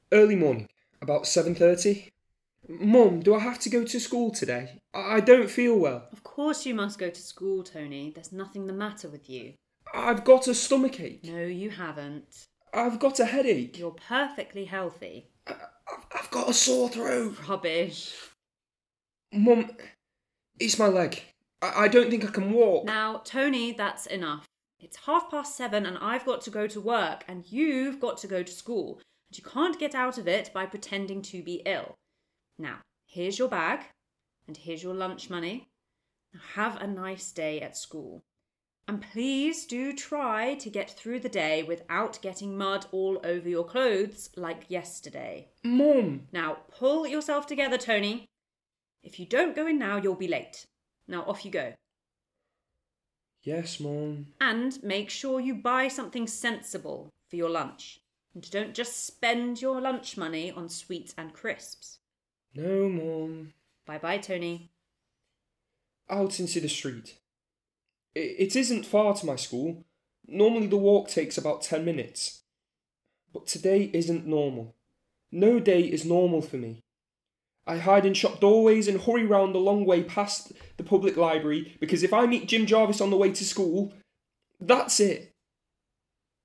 Ein Theaterstück über Mobbing – ernst, bewegend, aufrüttelnd